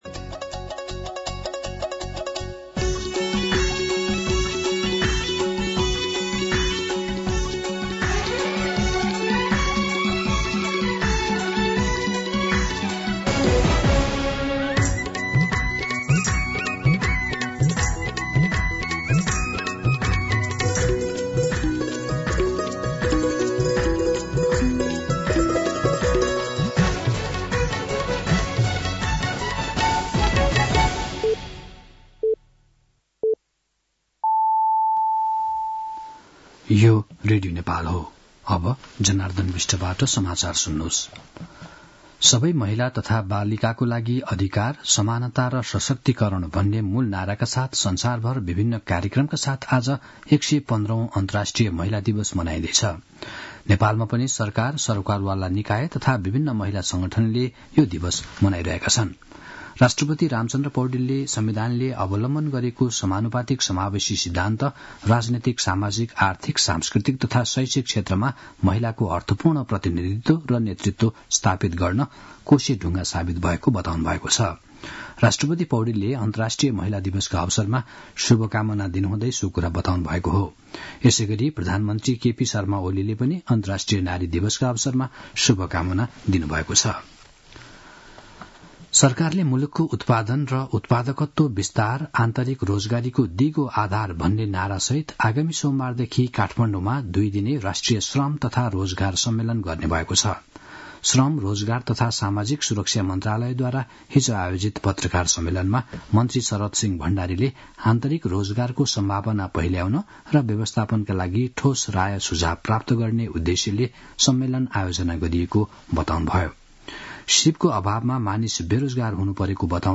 मध्यान्ह १२ बजेको नेपाली समाचार : २५ फागुन , २०८१